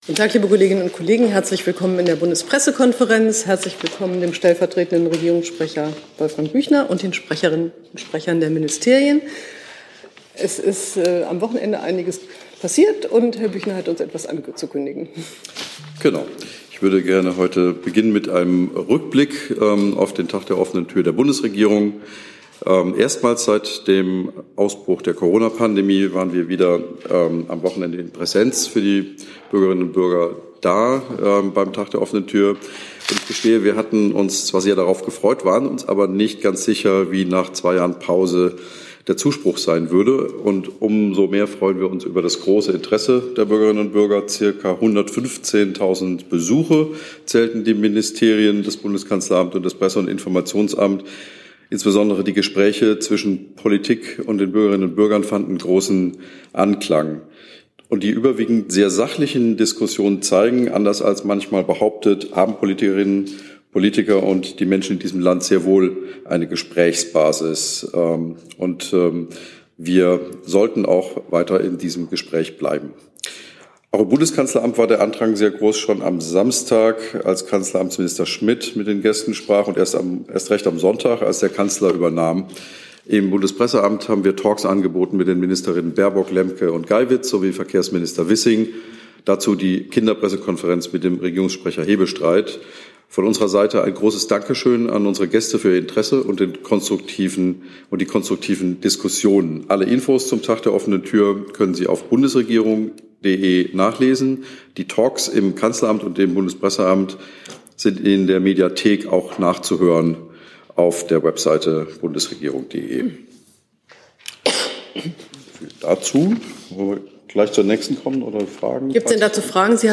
Regierungspressekonferenz in der BPK vom 22. August 2022